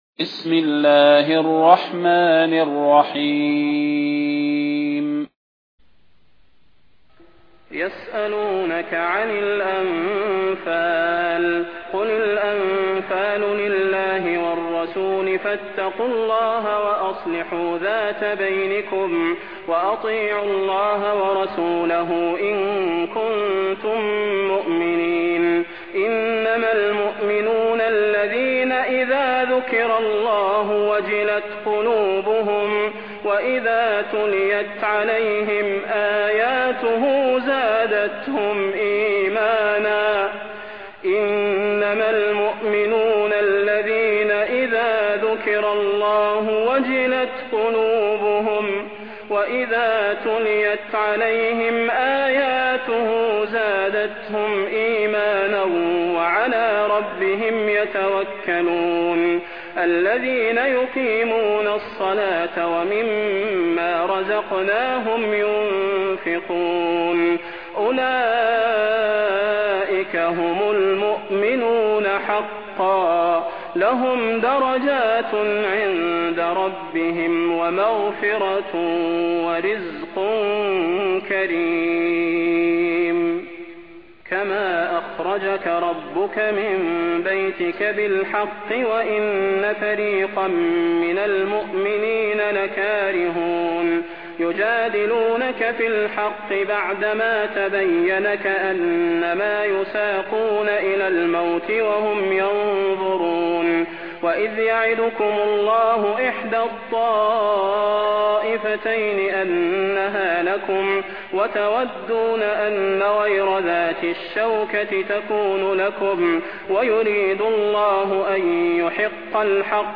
المكان: المسجد النبوي الشيخ: فضيلة الشيخ د. صلاح بن محمد البدير فضيلة الشيخ د. صلاح بن محمد البدير الأنفال